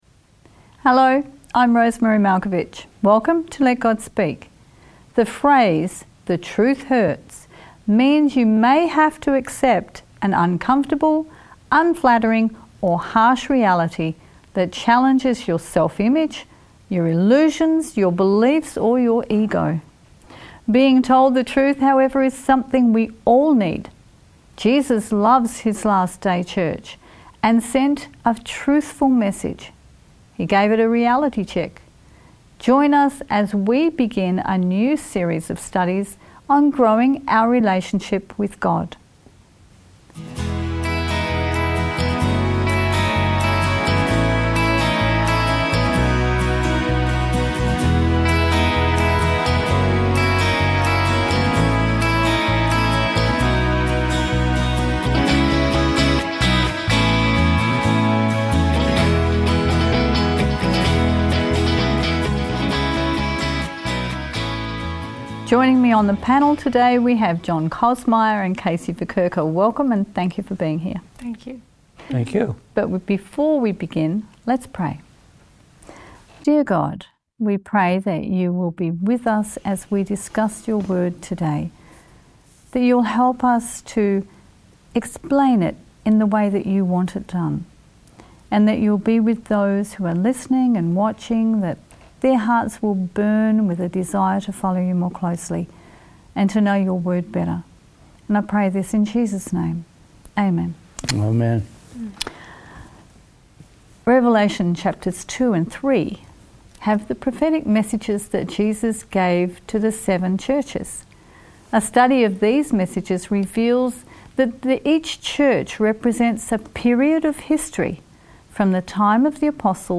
Jesus loves His last day church and sent it a truthful message. He gave it a reality check. Join us now for the beginnings of a new series of lessons on growing our relationship with God.